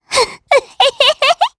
Pansirone-Vox_Happy1_jp.wav